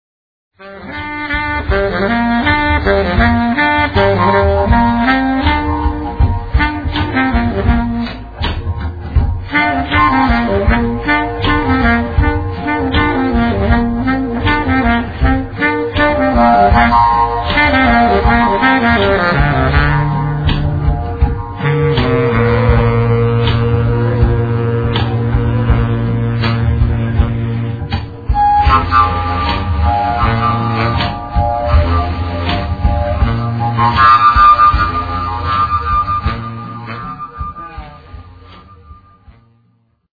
freejazz
baritone saxophone / tarogato
el. doublebass
drums
fujara, midi guitar, el. guitar). Live from Brno [2002].